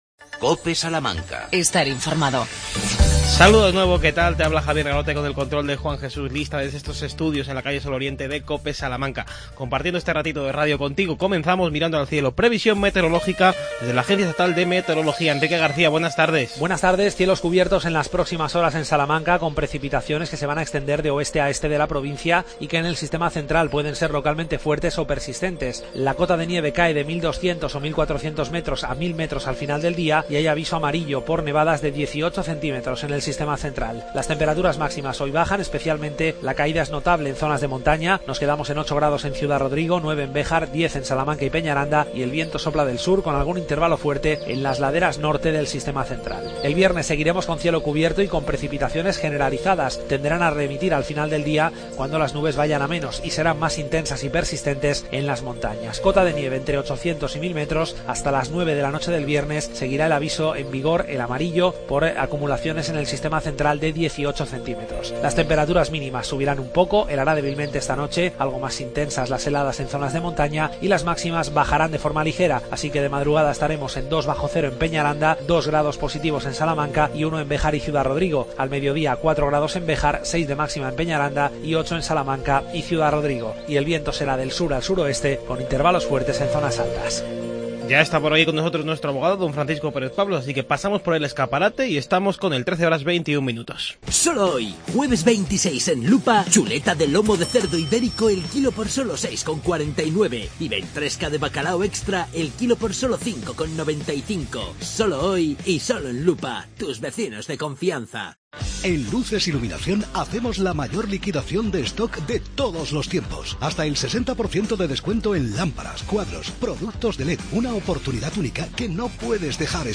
que responde dudas de los oyentes.